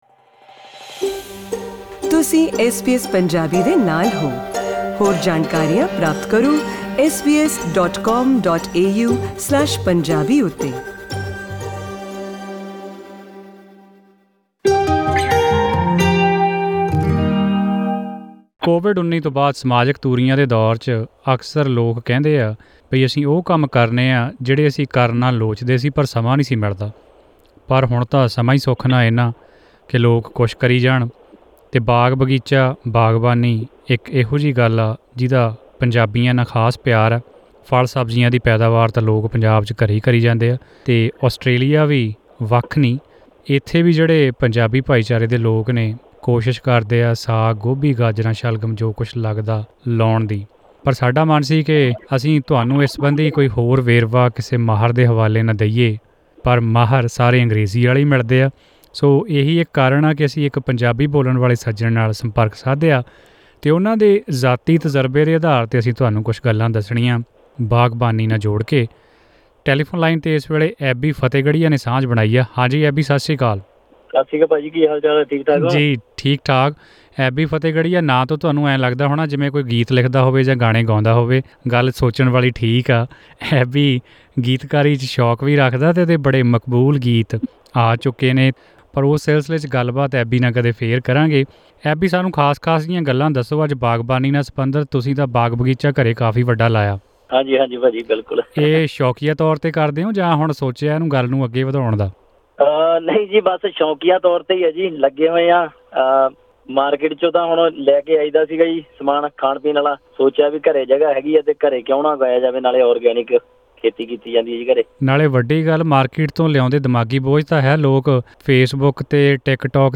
ਐਸ ਬੀ ਐਸ ਪੰਜਾਬੀ